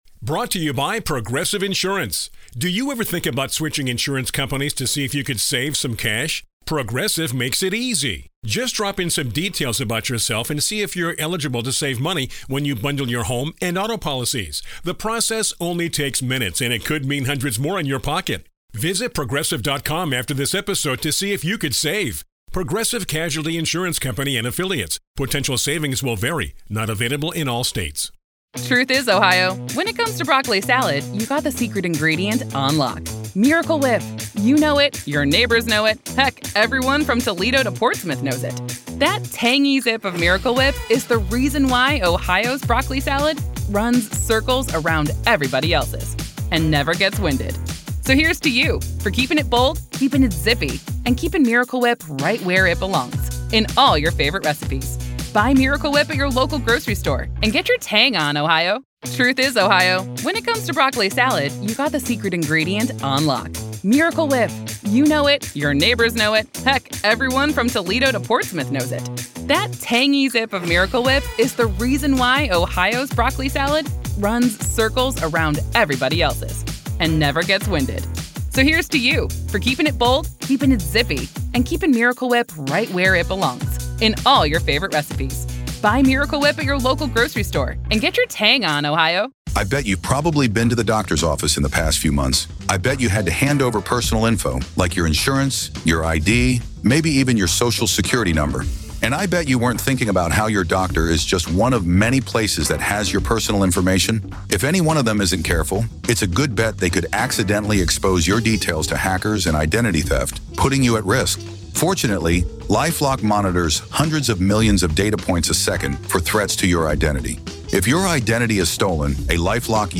the panel dissects how social media outrage, tribal thinking, and dopamine-driven rage responses have turned political discourse into psychological warfare.